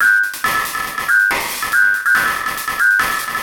E Kit 16.wav